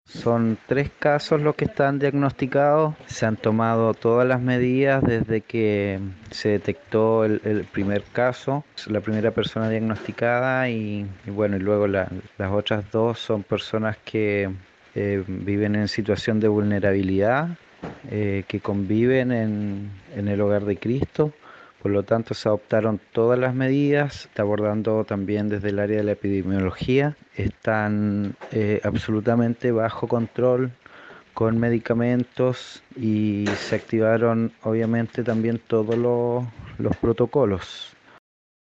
En tanto, el gobernador de la provincia de Aysén Marco Saavedra sostuvo que son tres los casos detectados, por lo que hay preocupación por sus estado de salud, pero bajo control por los equipos respectivos.
GOBERNADOR-AYSEN.mp3